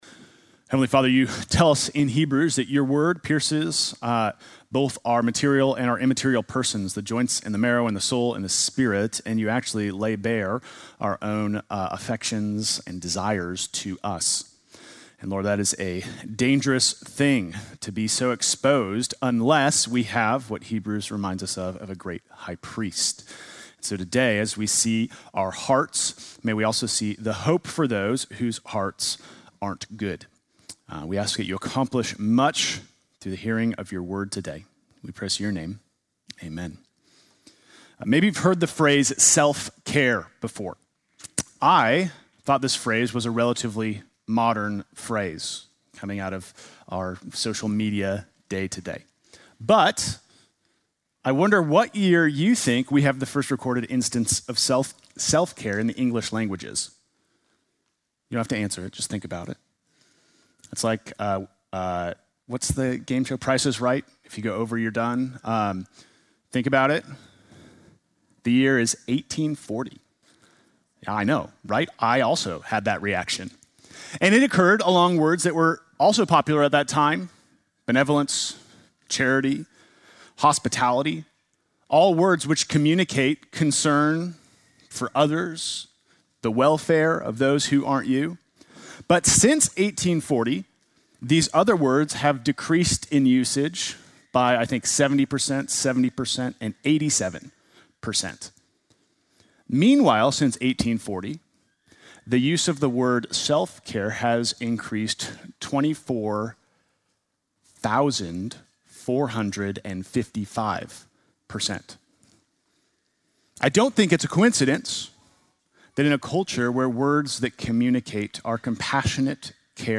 Sunday morning message September 7